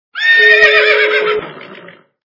» Звуки » Природа животные » Лошадь - Ржания
При прослушивании Лошадь - Ржания качество понижено и присутствуют гудки.
Звук Лошадь - Ржания